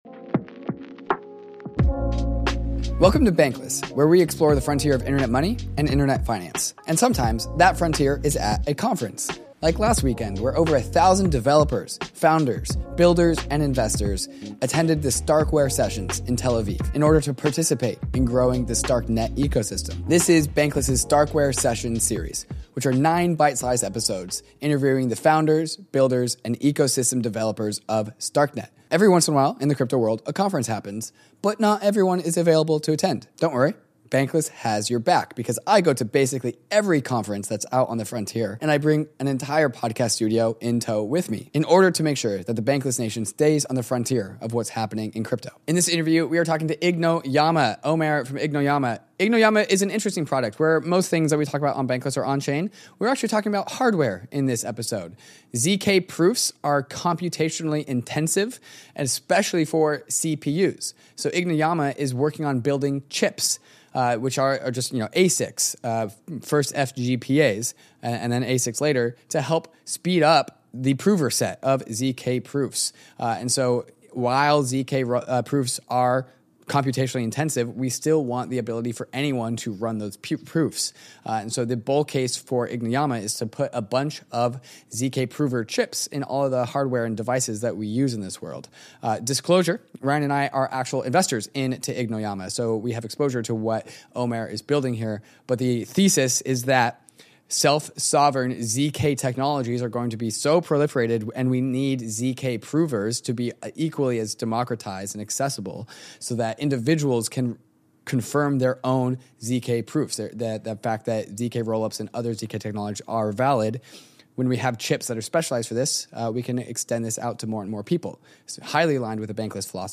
We’re returning from our adventures in Tel Aviv with nine exclusive interviews with some of the key players in the StarkNet space.